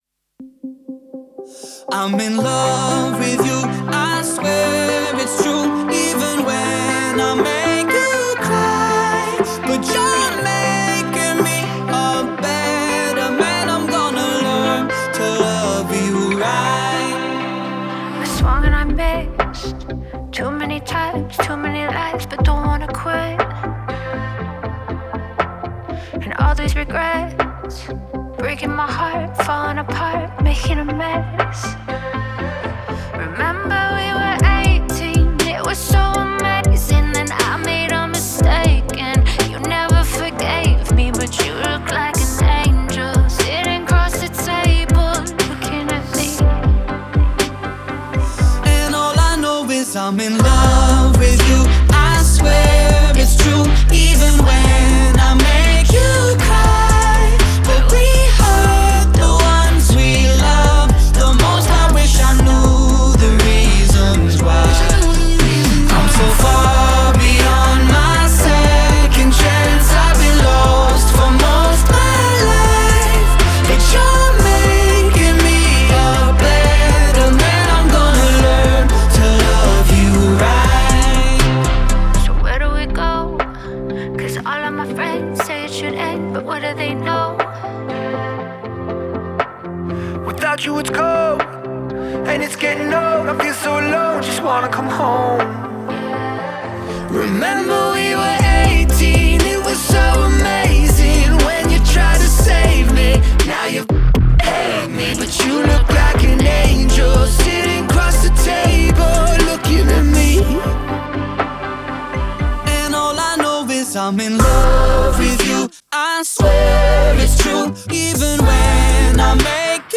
яркая и мелодичная поп-песня